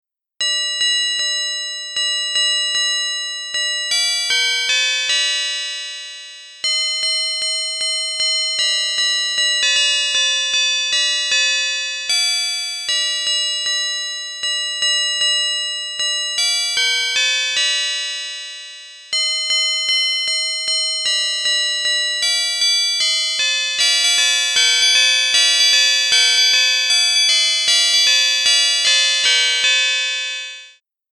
明るく軽快なメロディーが、クリスマスムードを盛り上げてくれます。
楽器